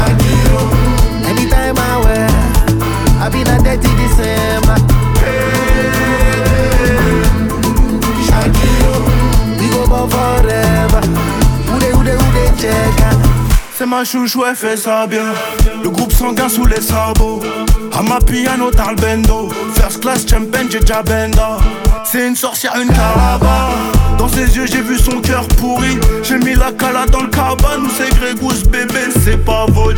Afro-fusion African Hip-Hop Rap
Жанр: Хип-Хоп / Рэп